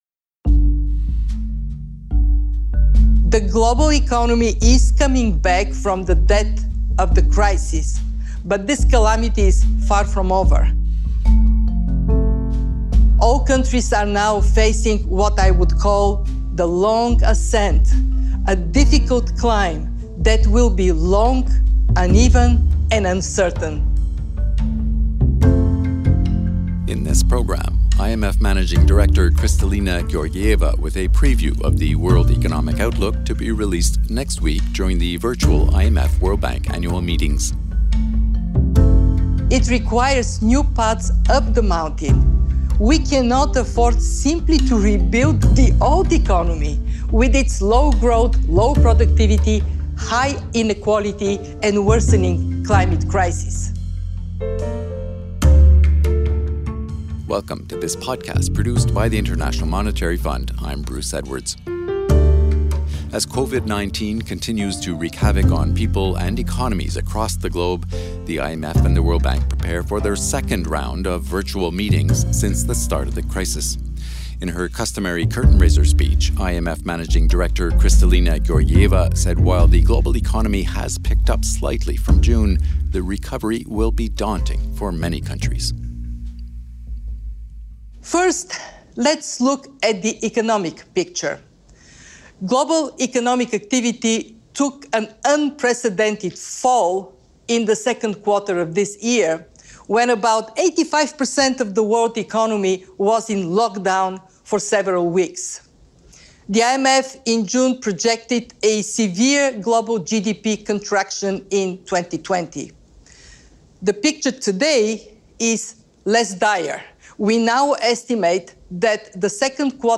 In her customary curtain raiser speech, IMF Managing Director, Kristalina Georgieva says while next week's outlook will show a small upward revision to the 2020 global forecast, countries are facing what she calls "The long ascent"—a difficult climb that will be uneven, uncertain and prone to setbacks.